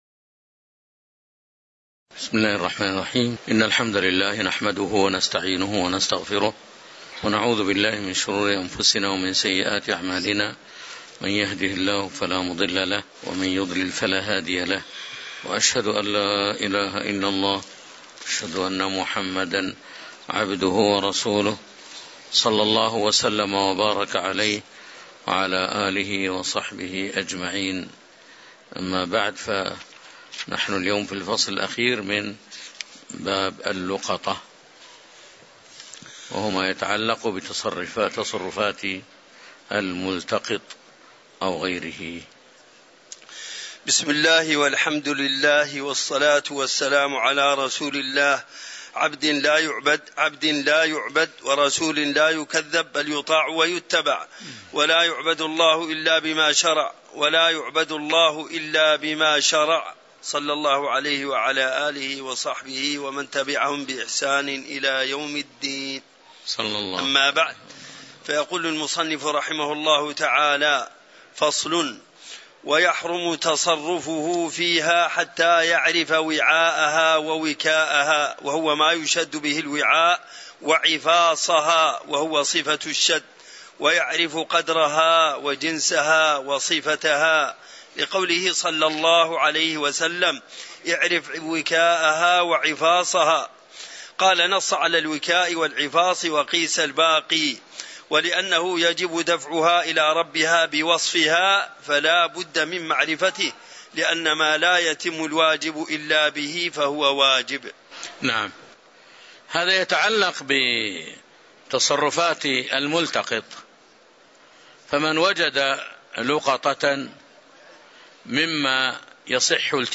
تاريخ النشر ٢٥ محرم ١٤٤٤ هـ المكان: المسجد النبوي الشيخ